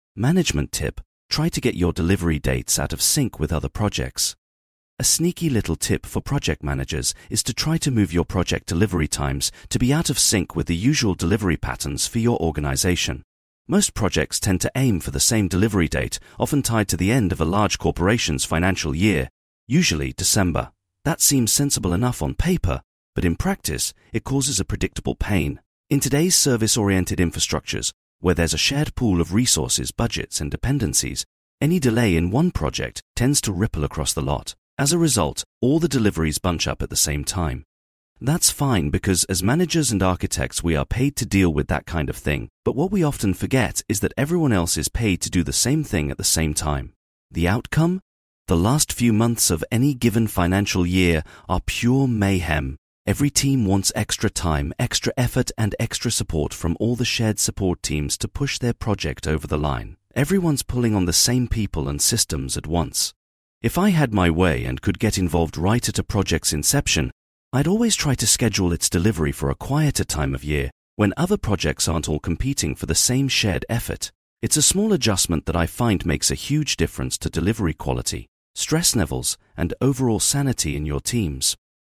I use one both to ensure all my blog posts have an audio version and because, as someone who is badly dyslexic, I find it the best way of proofreading my work.